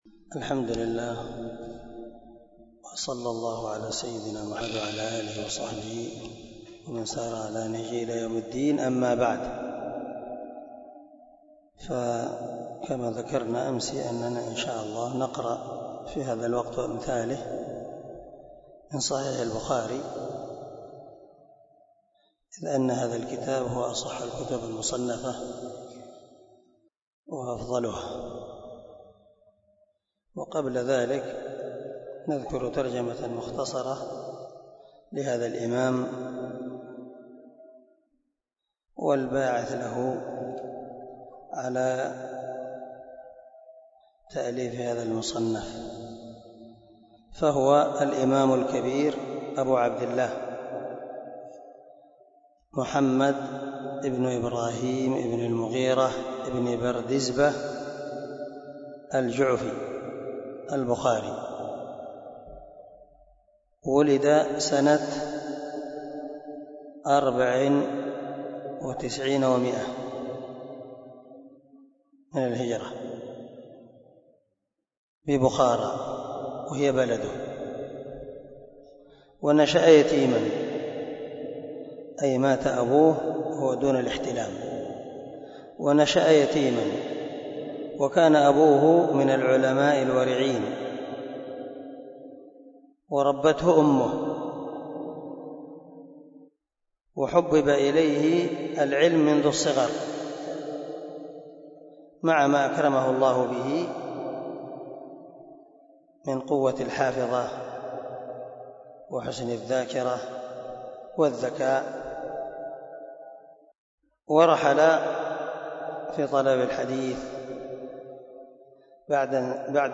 001الدرس 1 من ترجمة الإمام البخاري رحمه الله
دار الحديث- المَحاوِلة- الصبيحة.